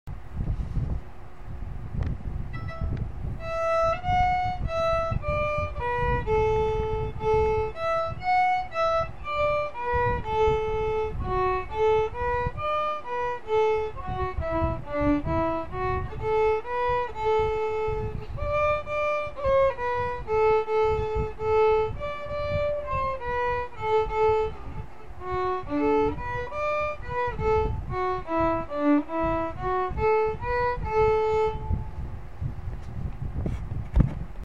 I also hear only two to three inches of bow being used.
That is played on my Cecilio CVN-EAV and FM's new strings.
I can't name the tune but it does sound an Irish folk tune.